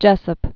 (jĕsəp), Cape